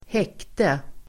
Uttal: [²h'ek:te]